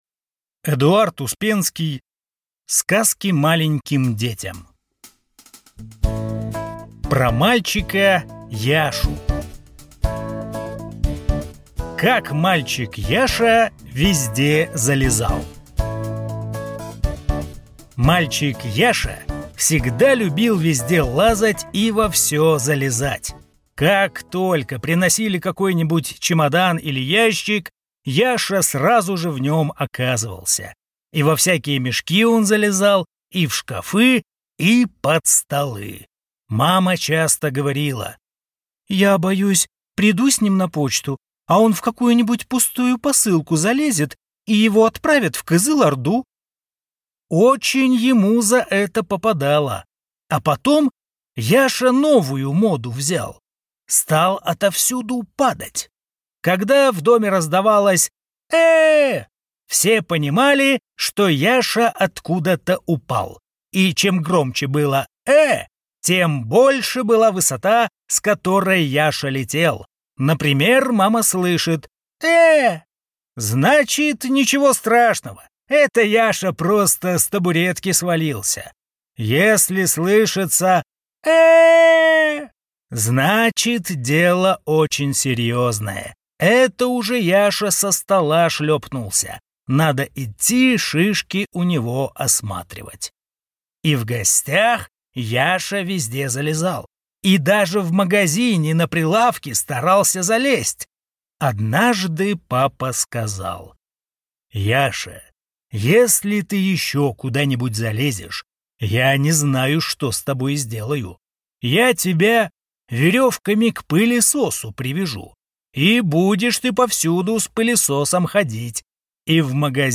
Аудиокнига Сказки маленьким детям | Библиотека аудиокниг